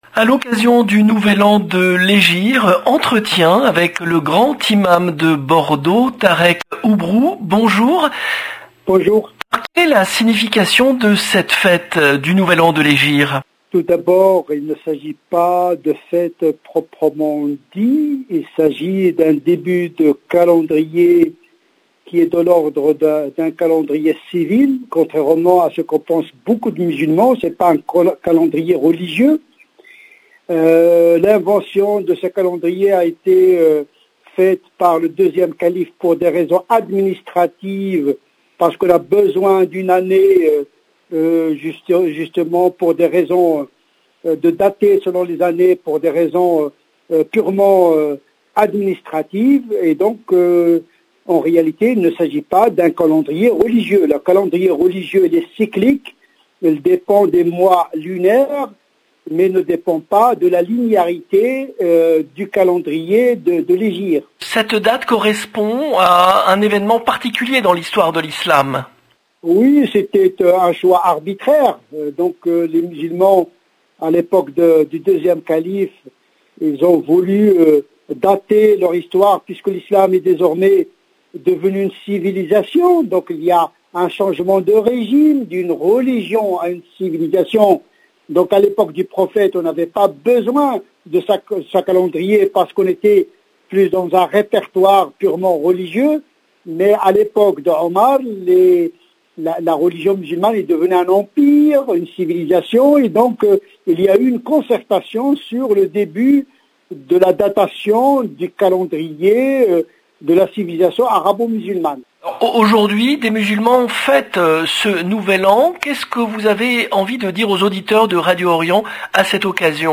A l'occasion du nouvel an de l'Hegire, entretien